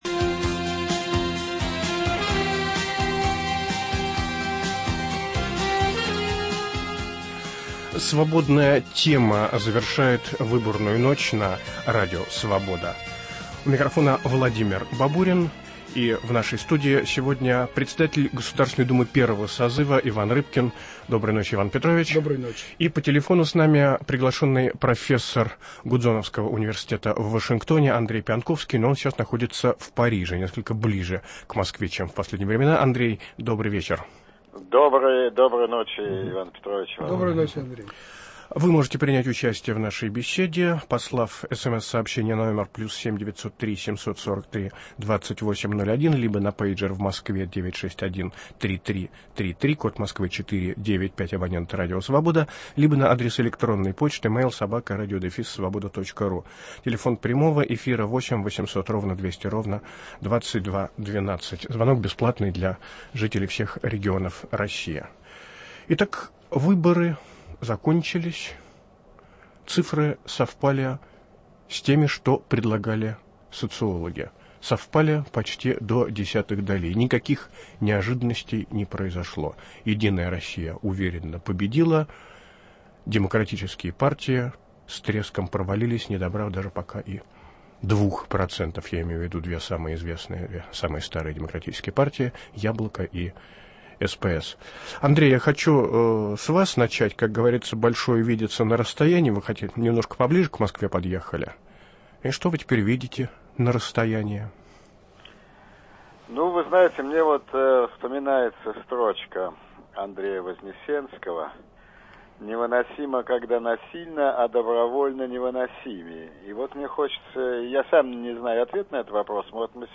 В студии - председатель Государственной Думы первого созыва Иван Рыбкин, по телефону - приглашенный профессор Гудзоновского университета в Вашингтоне Андрей Пионтковский.